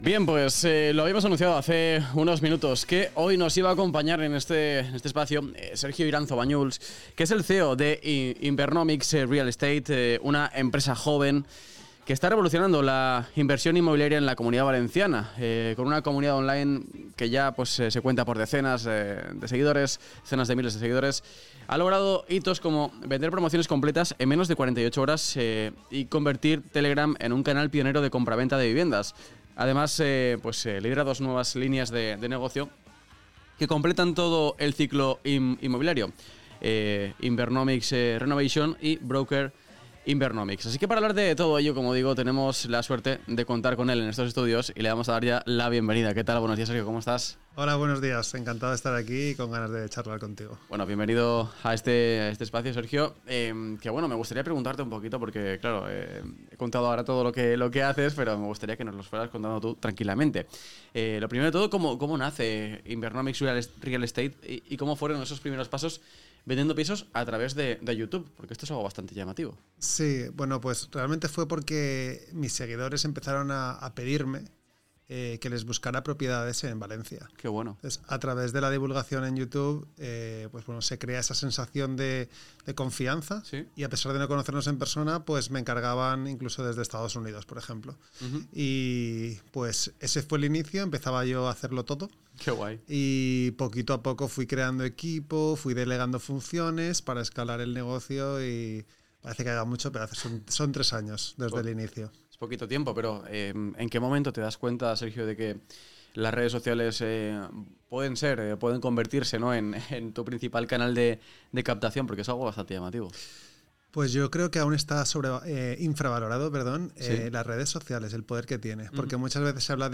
Entrevista
en el espacio de AJEV en Valencia Capital Radio